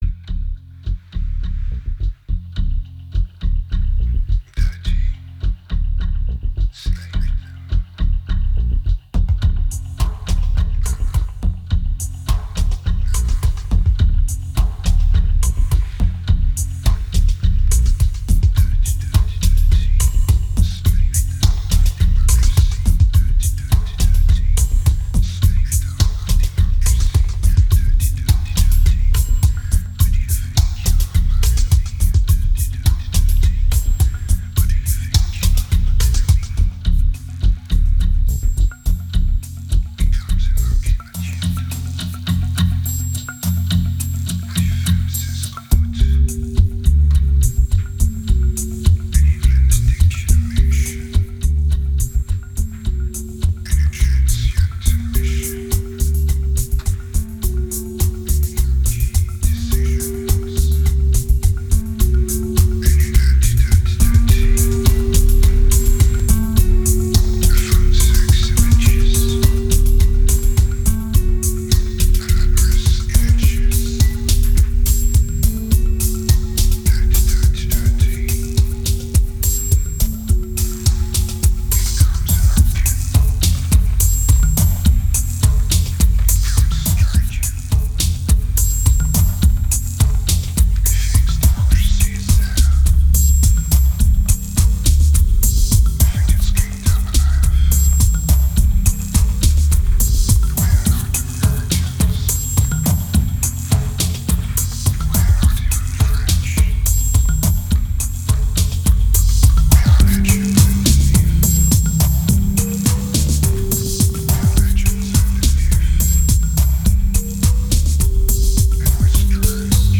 2181📈 - 1%🤔 - 105BPM🔊 - 2010-03-11📅 - -138🌟